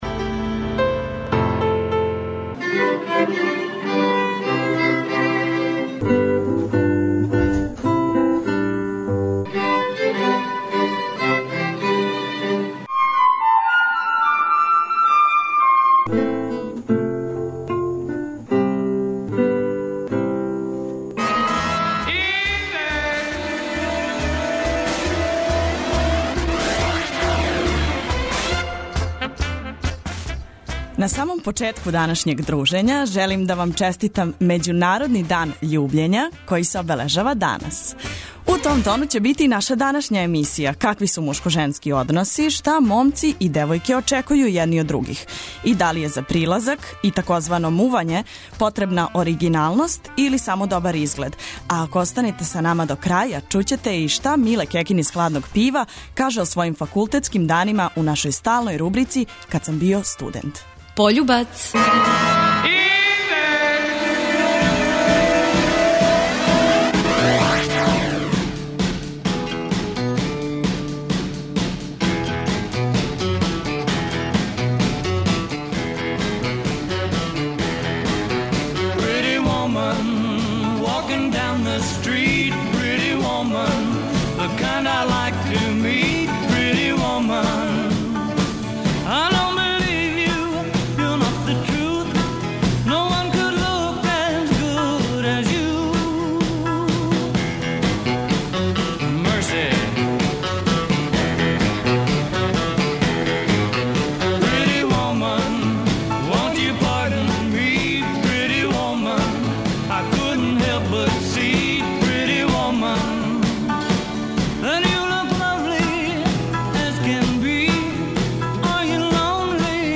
У рубрици ,,Кад сам био студент" о својим факултетским данима нам прича Миле Кекин из Хладног Пива.
преузми : 18.92 MB Индекс Autor: Београд 202 ''Индекс'' је динамична студентска емисија коју реализују најмлађи новинари Двестадвојке.